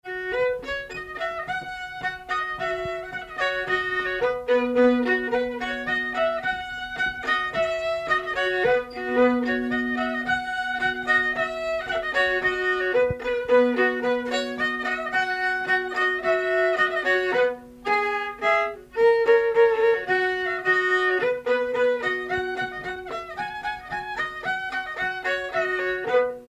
Air
Pièce musicale inédite